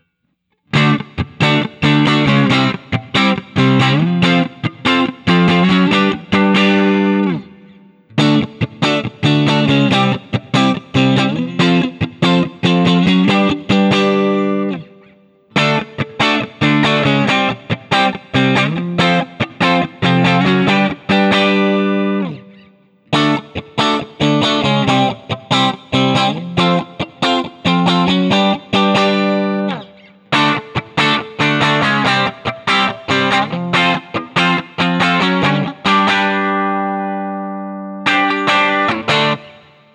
A Barre Chords
For the first five recordings I had the compressor on and I think it made everything sound a bit flat and harsh because as soon as I turned it off I liked it better, but I’m too lazy to re-record.
For most of the recordings I play my usual test chords through all five of the pickup settings in the following order: